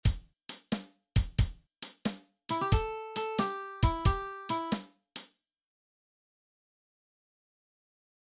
Licks sind für viele Rock- und Bluesstücke das Salz in der Suppe. Hier sind 10 Licks im ⁴/₄-Takt in E-Dur.
Als rhythmische Orientierung dient entweder das Metronom oder das Schlagzeug.